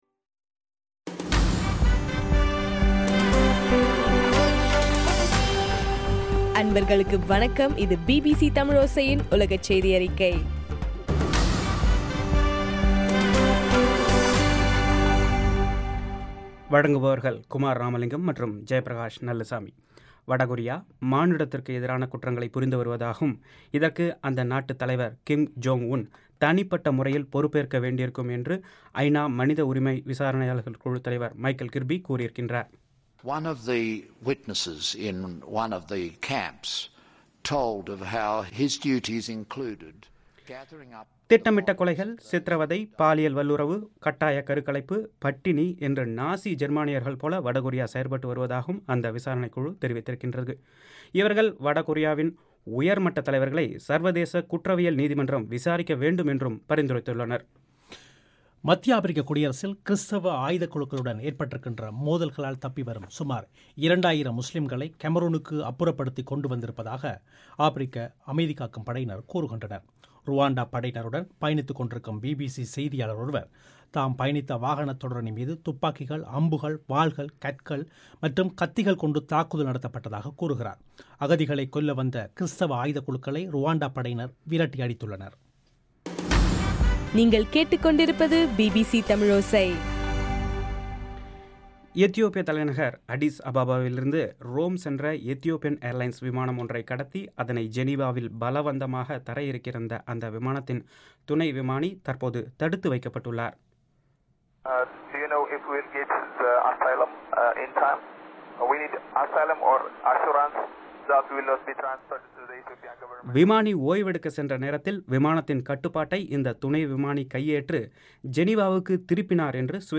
பிப்ரவரி 17 பிபிசியின் உலகச் செய்திகள்